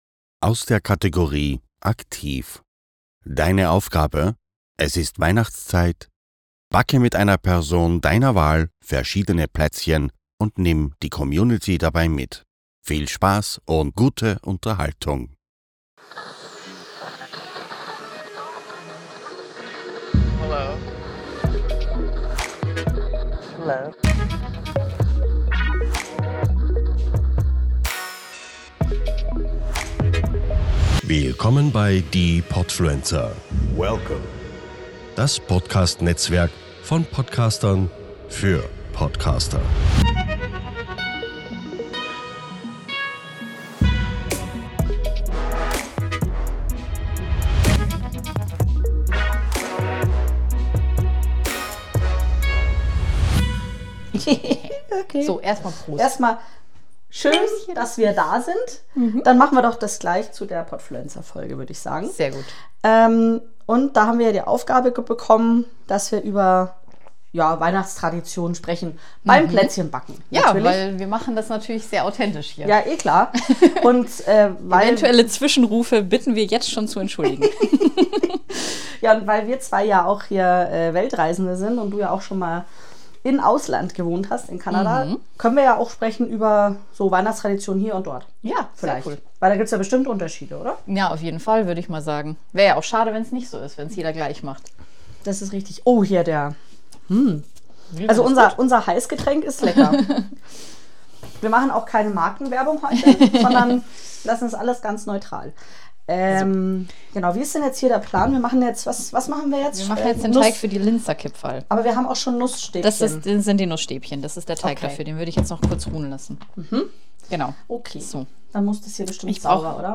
Ihr seid (fast) live beim Plätzchenbacken dabei.